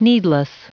Prononciation du mot needless en anglais (fichier audio)